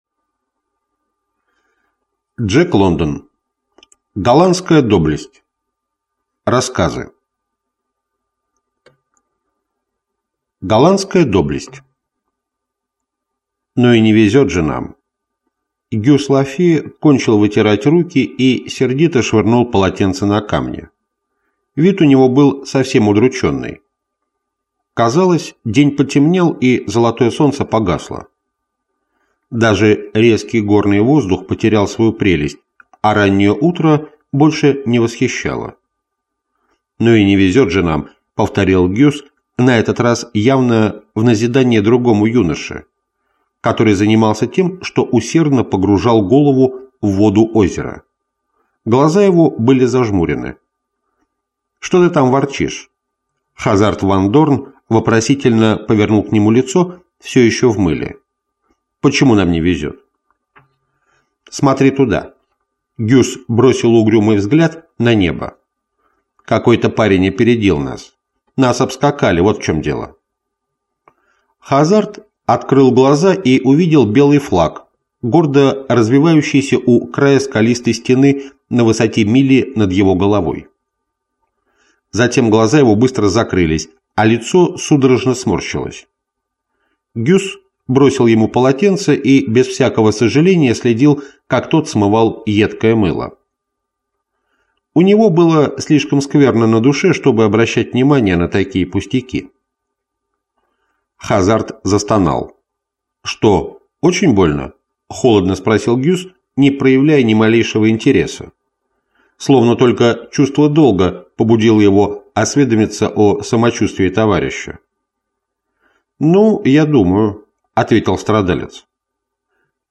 Аудиокнига Сын солнца. Голландская доблесть | Библиотека аудиокниг